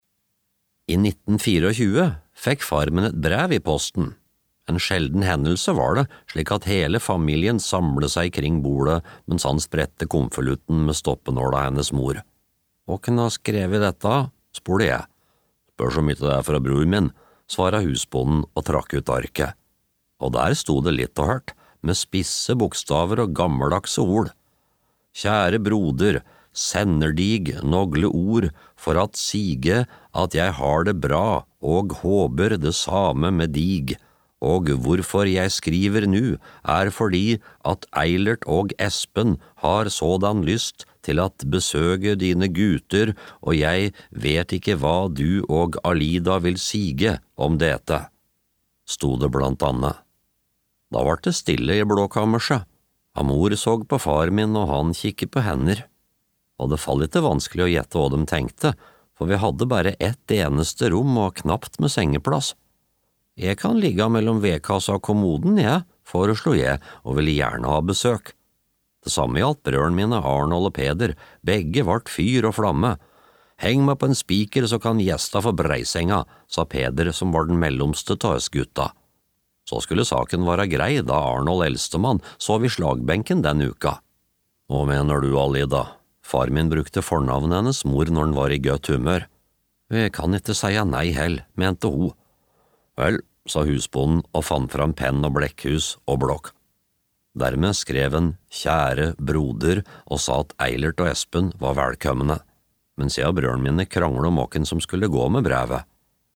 Kjære broder (lydbok) av Vidar Sandbeck